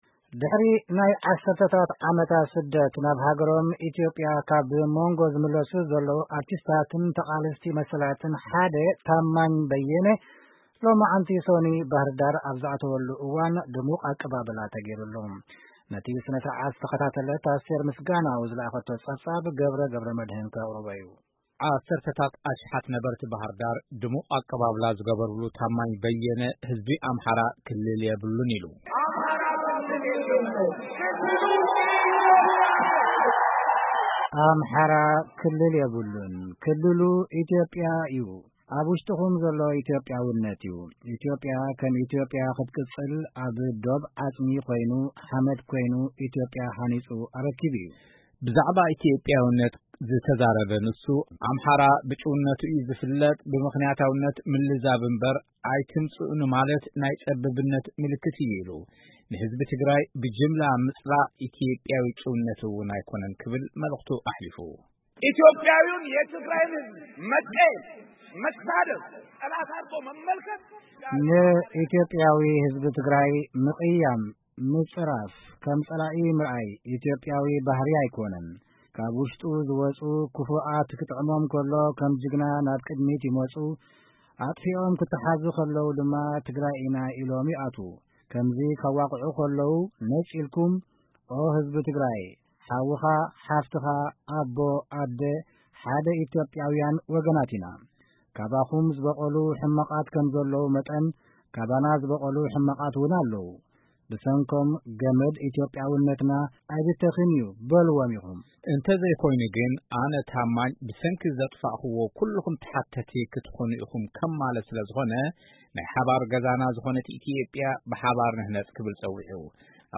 ስነ ስርዓት ኣቐባብላ ኣርቲስትን ኣክቲቪስትን ታማኝ በየነ ኣብ ከተማ ባህርዳር